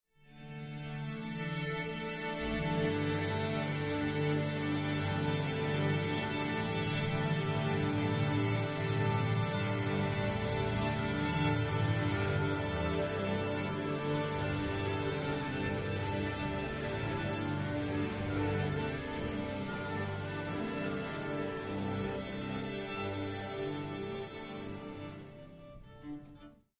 Orchestra
Orchestra Tuning Ambience 1 - Strings Mainly Long Symphonic - Musical